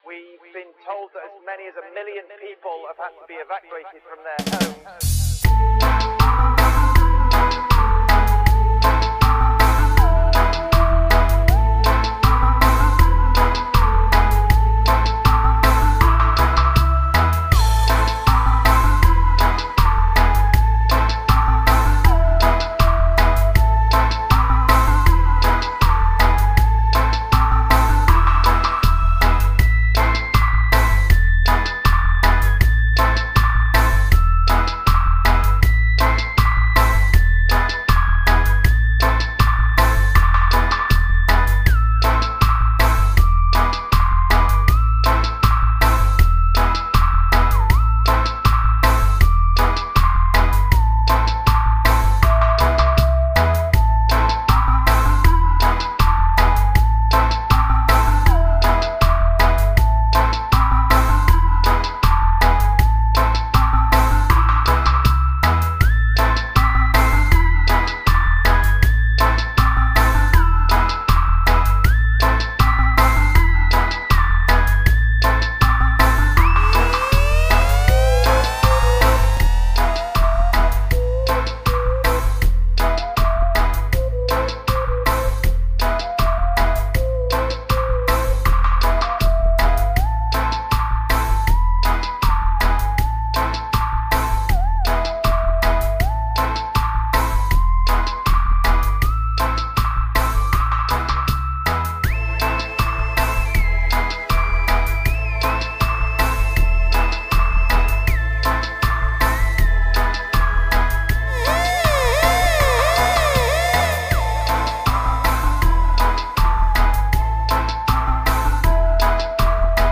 Radioshow